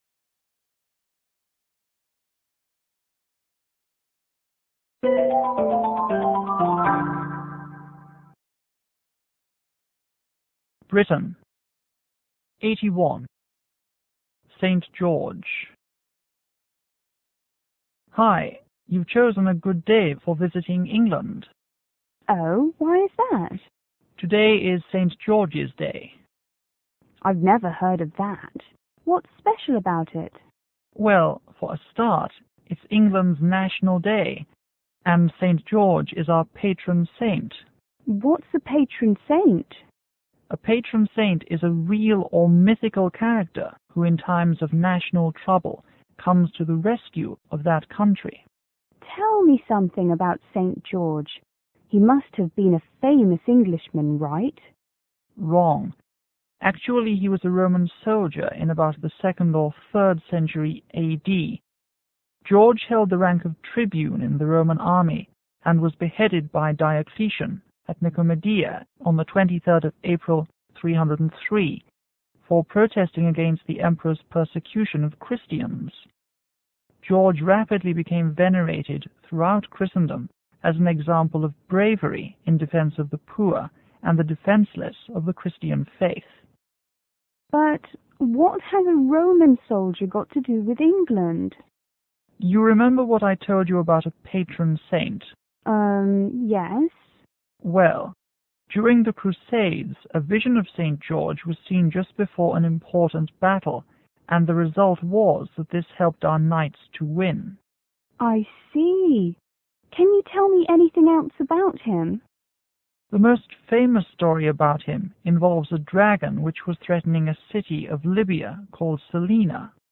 E: Englishman    T:Tourist